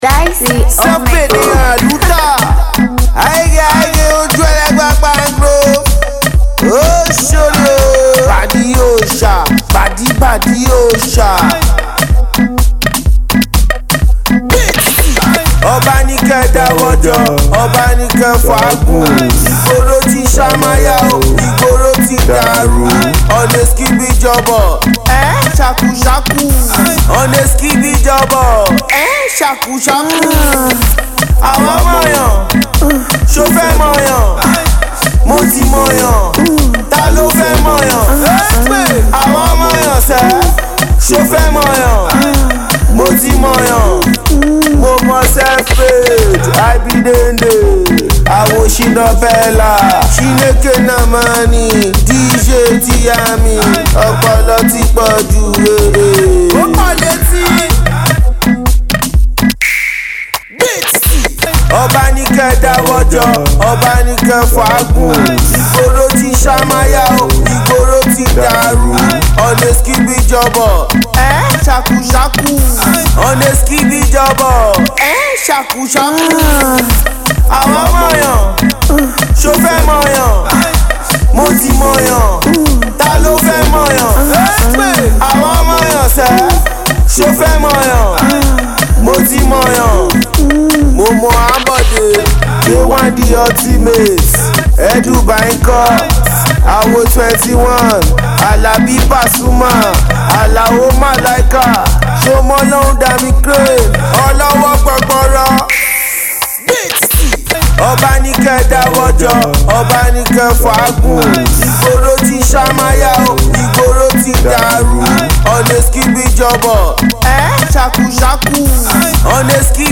street-tailored tune
groovy tune